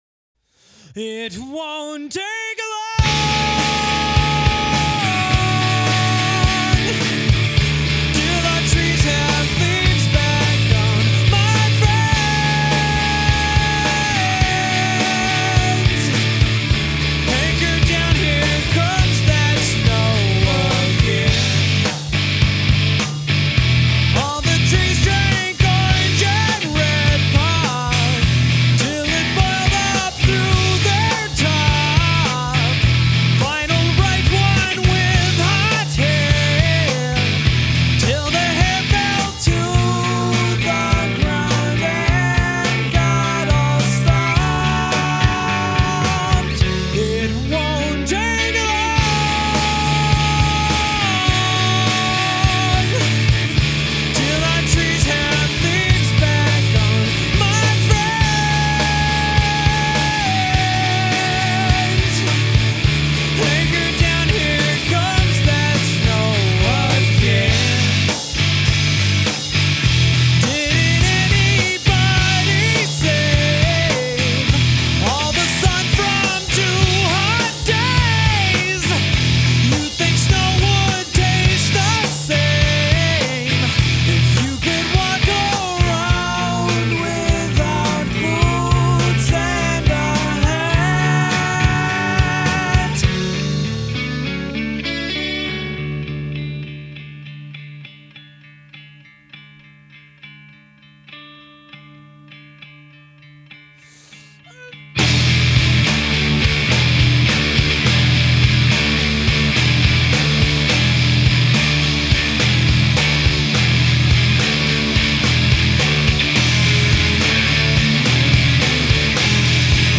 second guitar